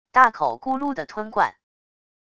大口咕噜的吞灌wav音频